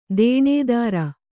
♪ dēṇēdāra